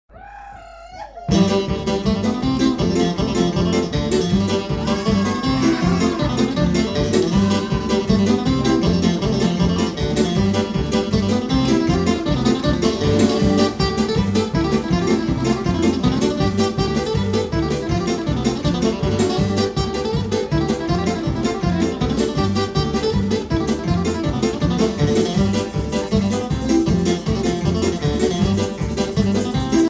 D'influences country, rock et celtique
Simple, efficace et très vivant.